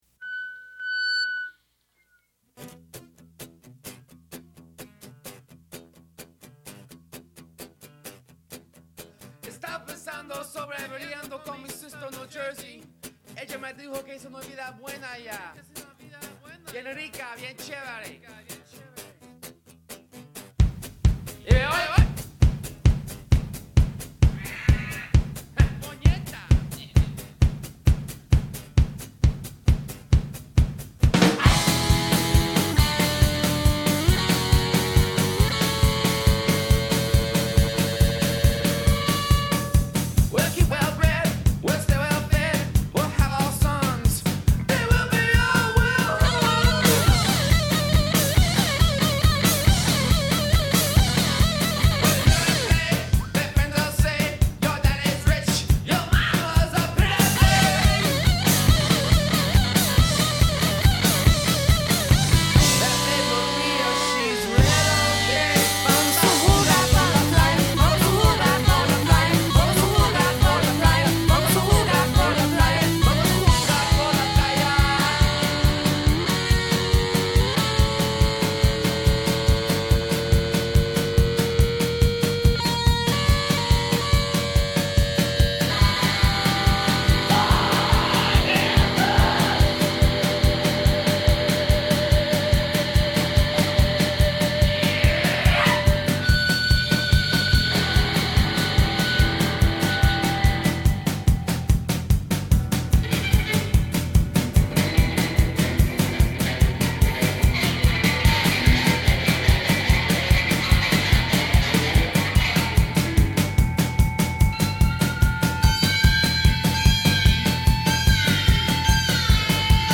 all menace and snarl.